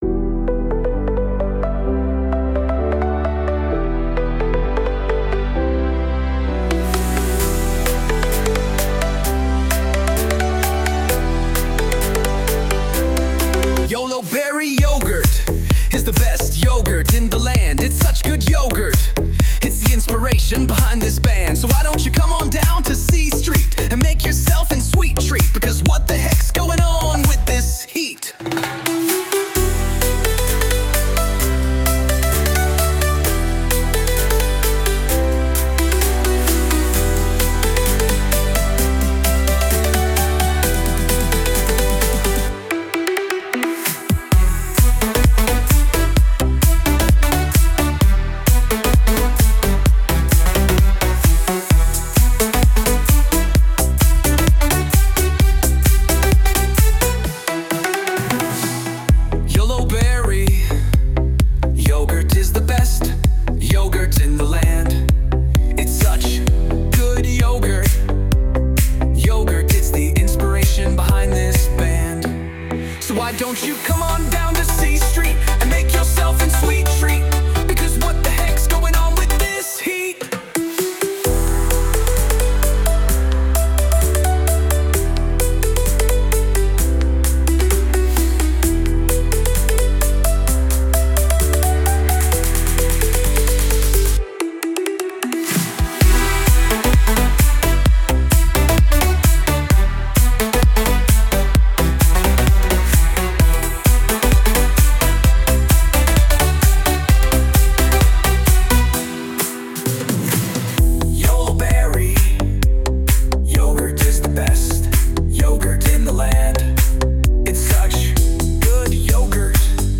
EDM Jingle